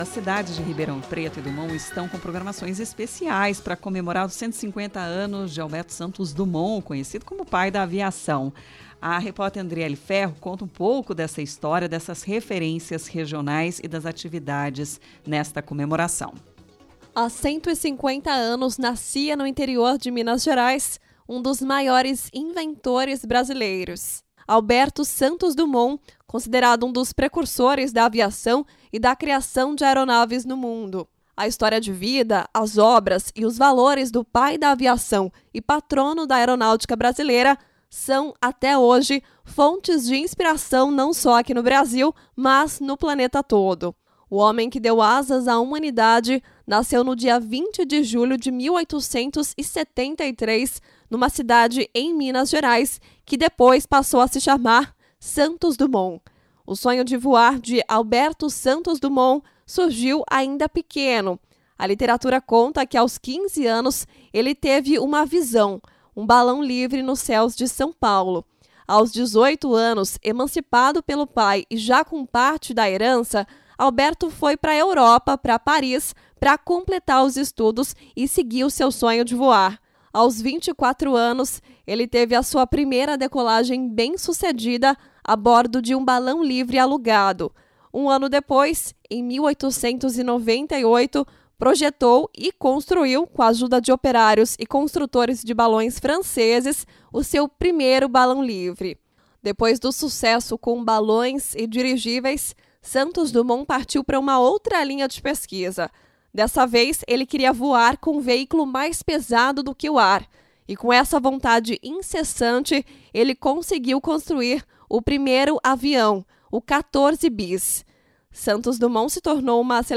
Clipping Entrevistas 20 de julho de 2023